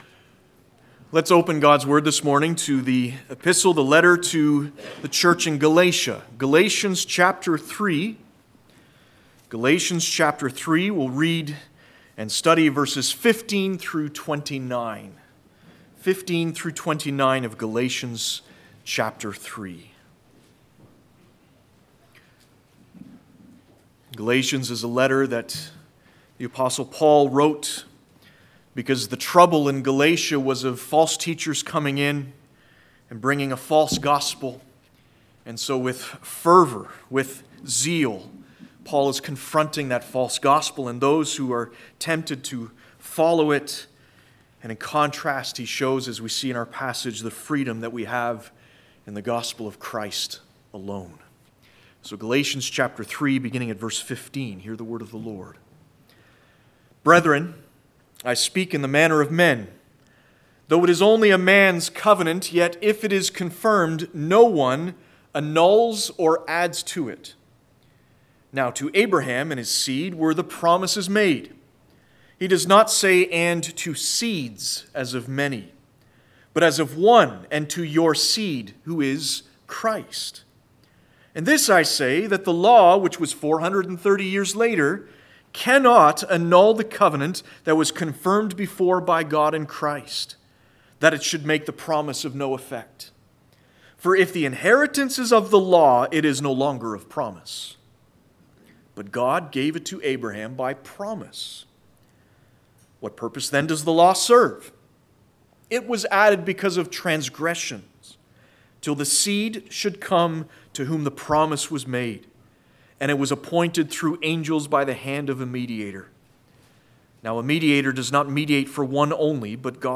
Passage: Galatians 3:15-29 Service Type: Sunday Morning « The All powerful Church is led by the All powerful Christ Jesus said